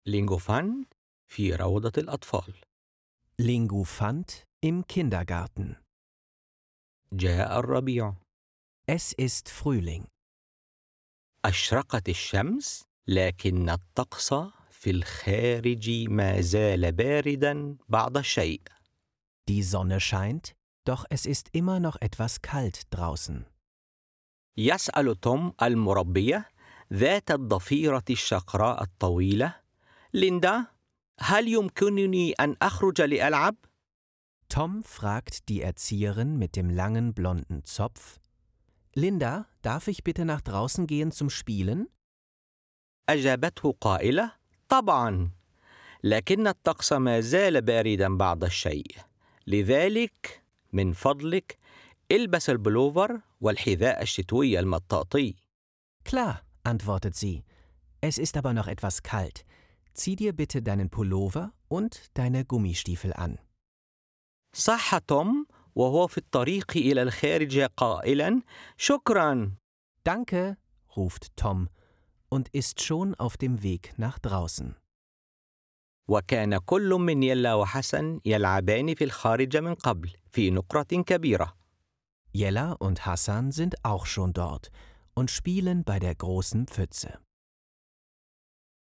Wie beim gemeinsamen Anschauen eines Bilderbuches beschreibt die Hörgeschichte auf der CD die Bilder auf dem beigelegten Poster in der Fremdsprache und der Muttersprache.